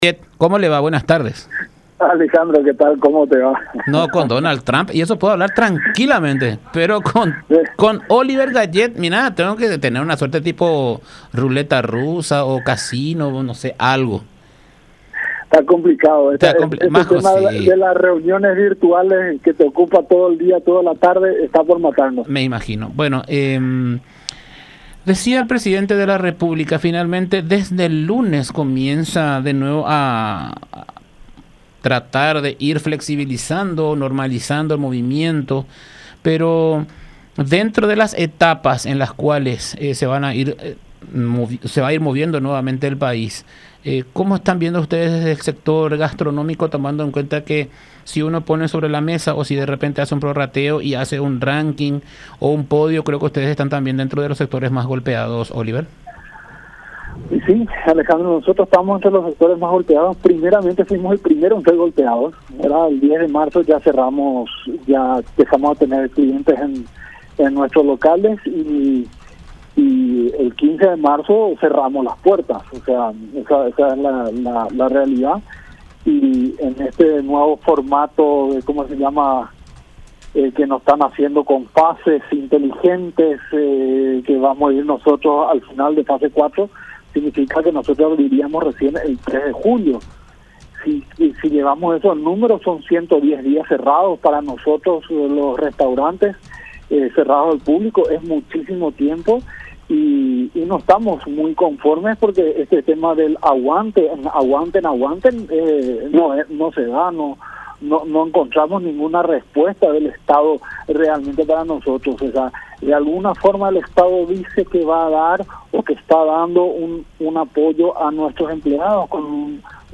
dialogo